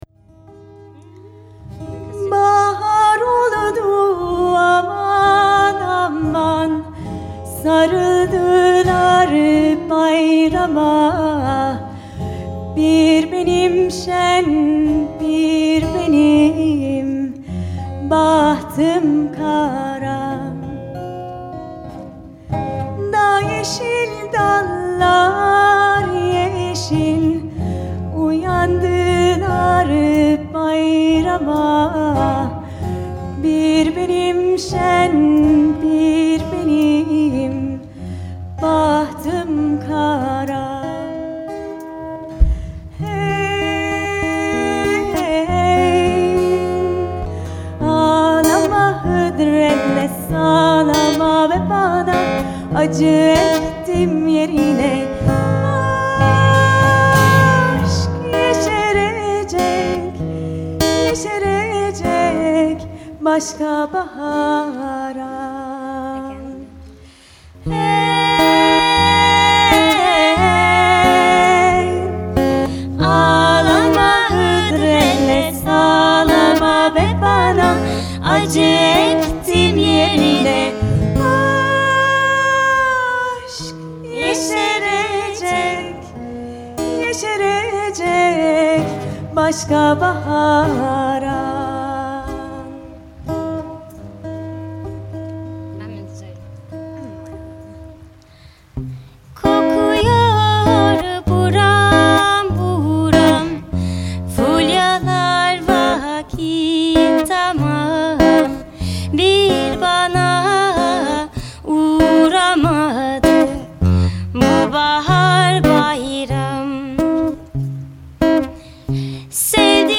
Turkish folk (live)
Recorded live at Rollright Fayre